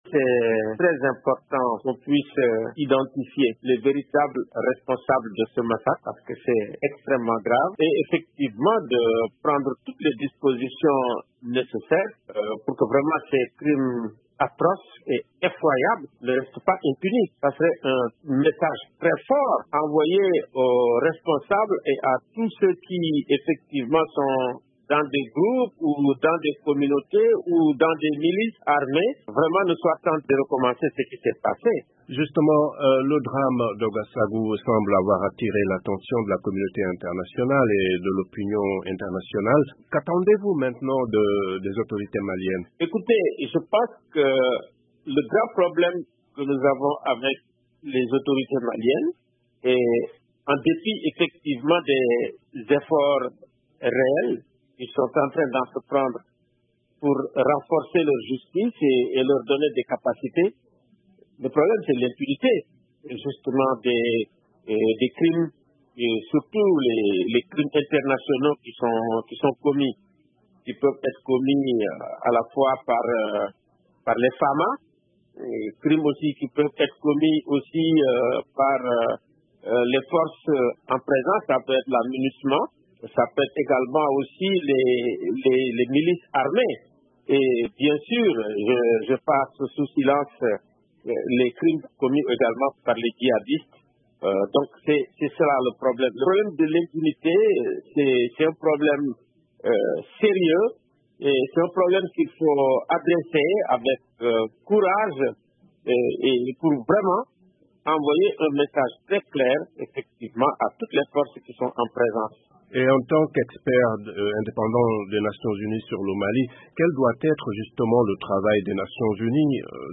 a joint à Dakar l’expert indépendant des Nations-unies sur les droits de l’homme au Mali, Alioune Tine.